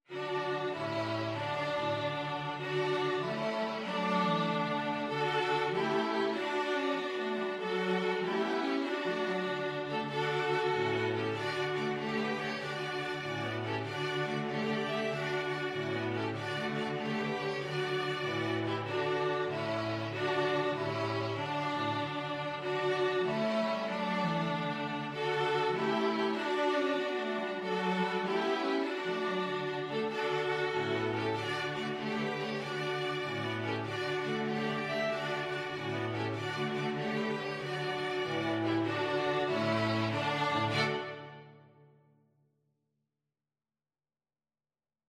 Childrens Childrens String Quartet Sheet Music Three Blind Mice
Violin 1Violin 2ViolaCello
6/8 (View more 6/8 Music)
D major (Sounding Pitch) (View more D major Music for String Quartet )
Quick two in a bar . = c. 96
String Quartet  (View more Easy String Quartet Music)
Traditional (View more Traditional String Quartet Music)